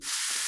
Party Monster Noise.wav